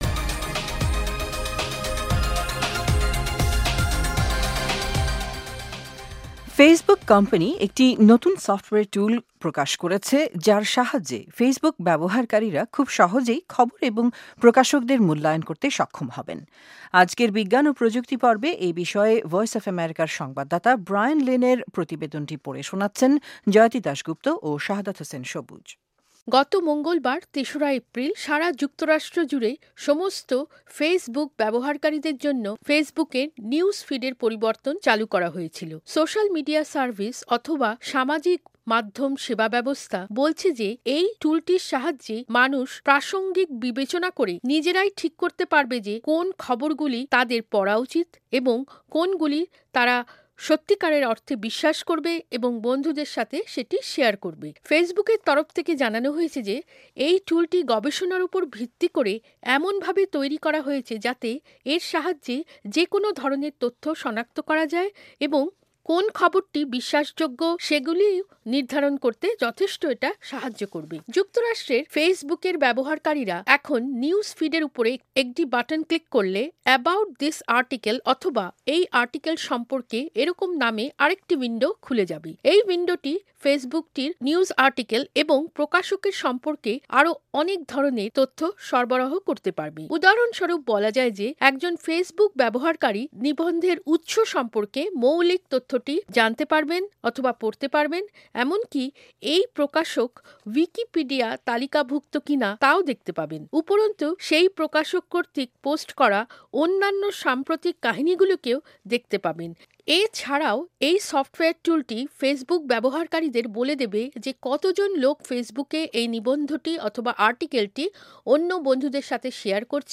প্রতিবেদনটি পড়ে শোনাচ্ছেন